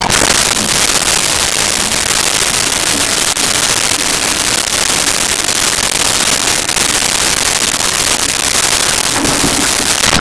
Toilet Flush
Toilet Flush.wav